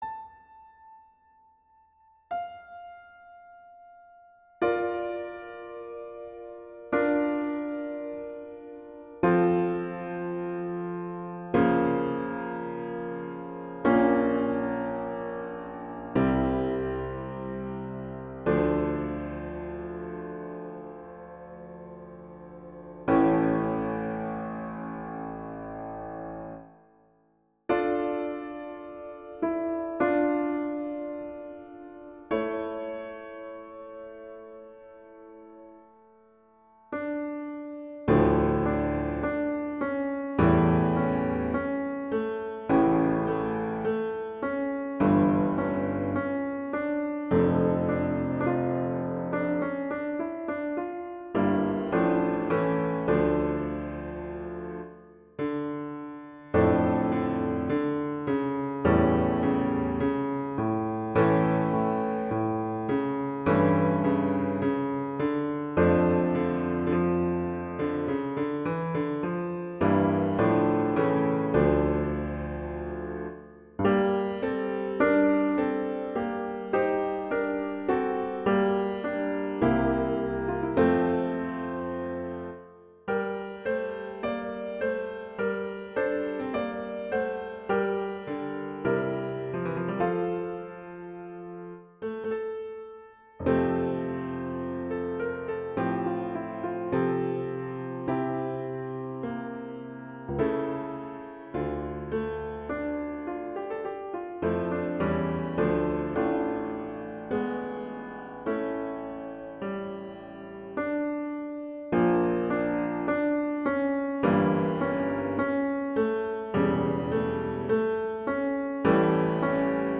Instrumentation: piano solo
transcription for piano solo
classical
D minor
♩=52 BPM